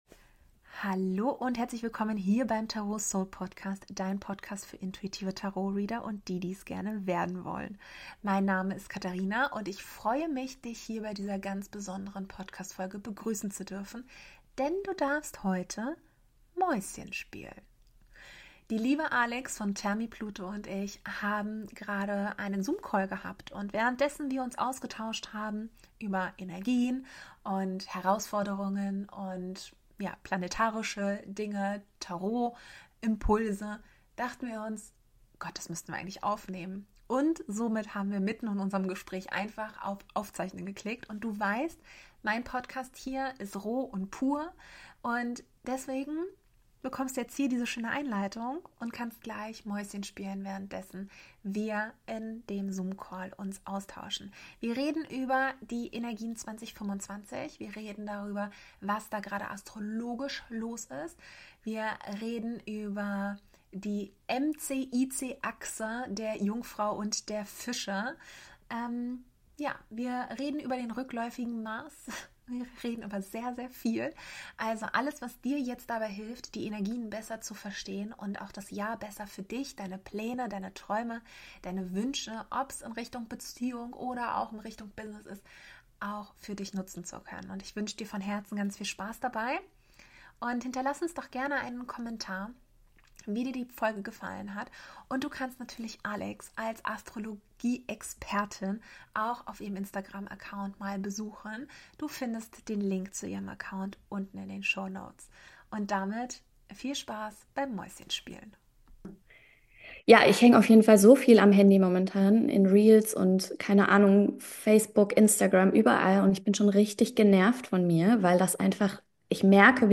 Lass uns gerne in den Kommentaren wissen, ob dir das Format gefällt und wir die Mäuschen-Klappe bald mal wieder öffnen sollen - nächstes Mal dann definitiv mit einer besseren Tonspur meinerseits.;) Und natürlich: Wenn dir der Podcast gefällt, dann würde ich mich sehr über deine 5-Sterne-Bewertung und deinen Support freuen.